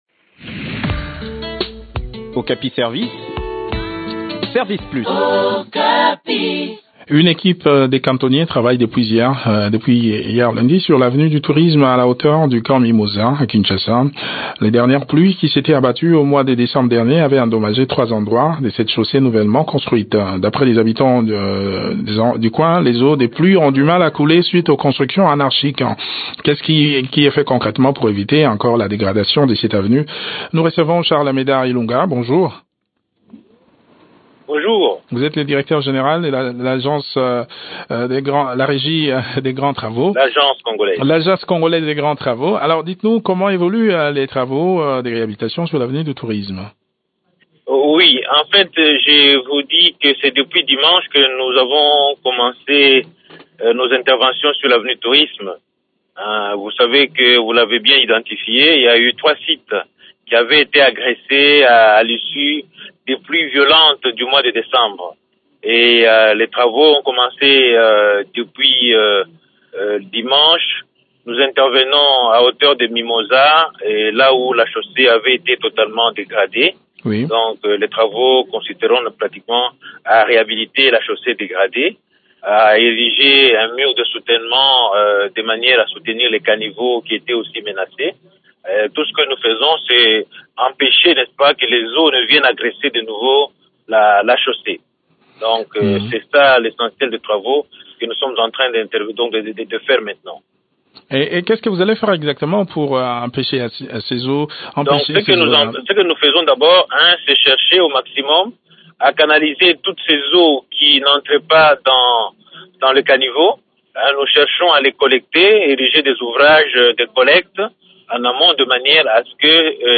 Les dernières pluies diluviennes avaient endommagé cette chaussée à trois endroits. Le point de la situation sur le terrain dans cet entretien